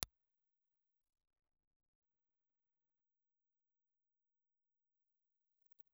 Impulse Response file of the RCA MI-3027E ribbon microphone.
RCA_3027E_IR.aiff